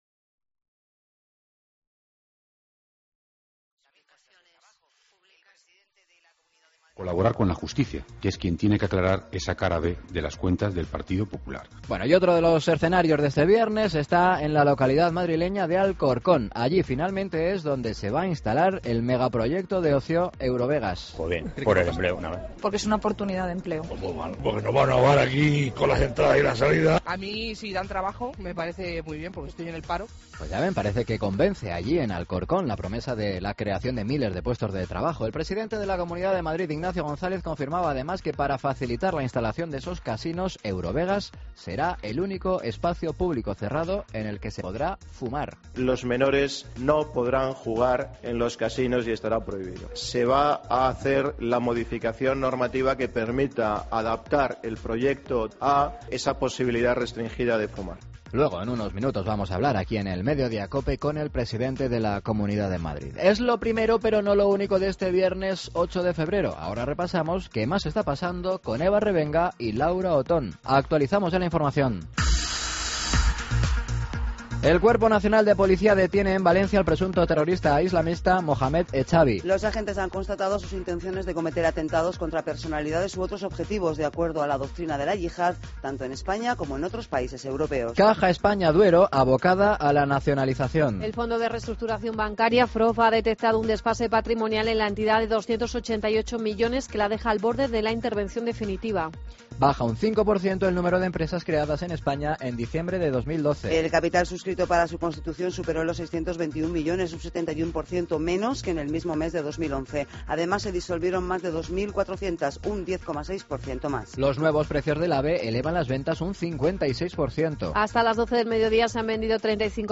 El Secretario General del Partido Popular Europeo (PPE), Antonio López Istúriz, ha afirmado en 'Madiodía COPE' que las negociaciones en Bruselas van "por la buena vía".